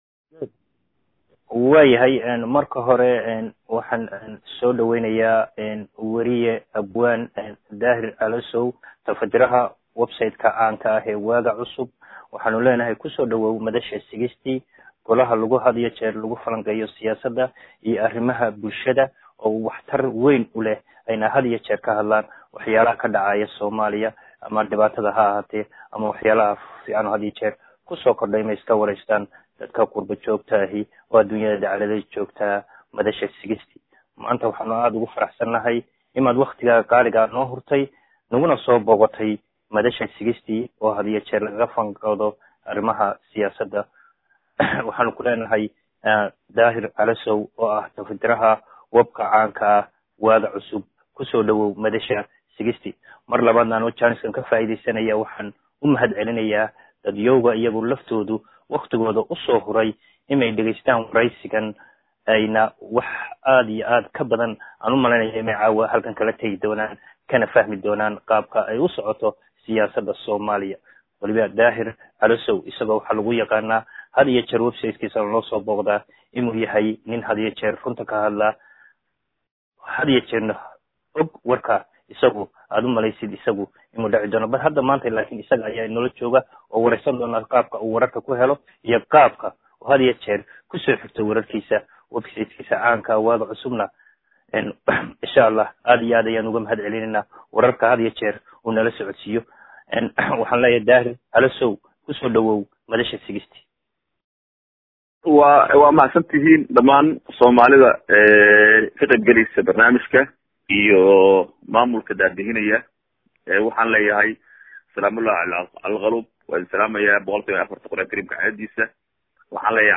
waana Gole Telefoon wadaag ah(Teleconference) oo furan 24ka saac maalin iyo habeenba